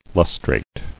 [lus·trate]